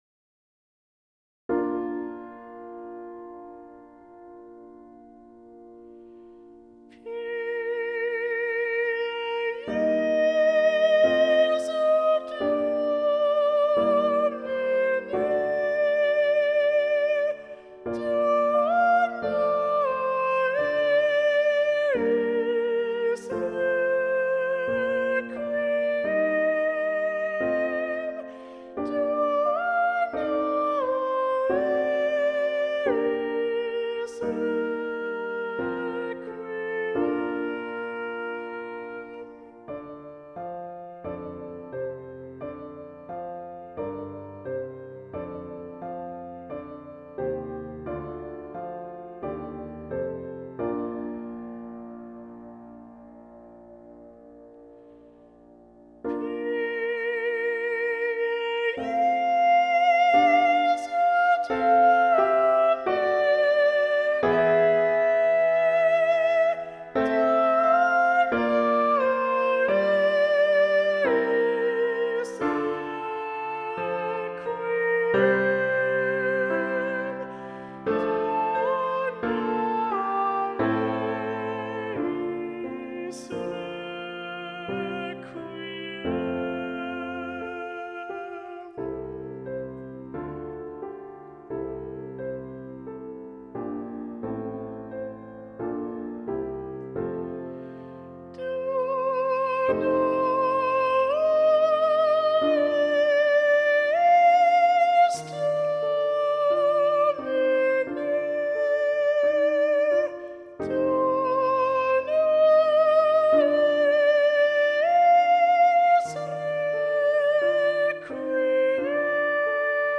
Index of /Rehearsal_Tracks/Faure_Requiem/Full Choir Access
Pie Jesu (Requiem) - Balanced Voices - Gabriel Faure, ed. John Rutter.mp3